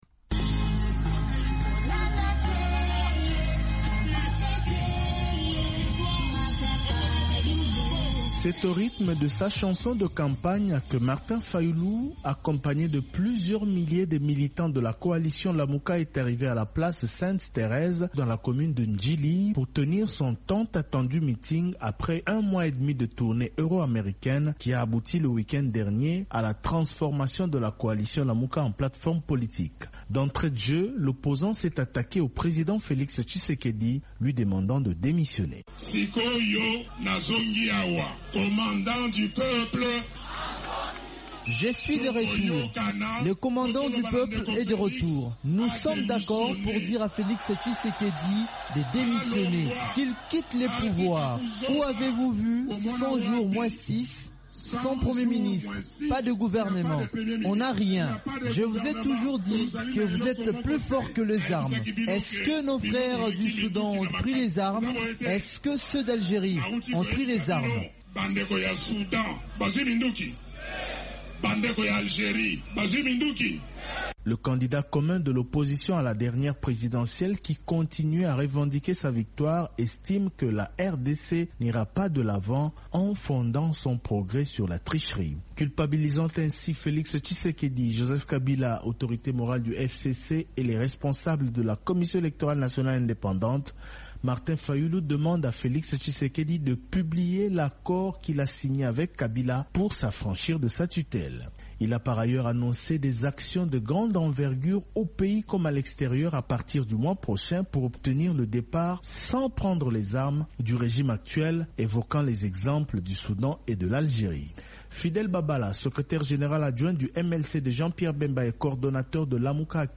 Martin Fayulu en meeting à Kinshasa
Retour sur le meeting que l’opposant congolais Martin Fayulu a tenu dimanche à Kinshasa. De retour en RDC après une tournée d’un mois et demi à l’étranger, le candidat commun de l’opposition à la dernière présidentielle s’en est pris à la gestion du président Félix Tshisekedi dont il a demandé la démission…